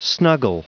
Prononciation du mot snuggle en anglais (fichier audio)
Prononciation du mot : snuggle